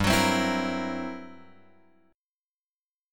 GmM13 chord {3 x 4 5 5 6} chord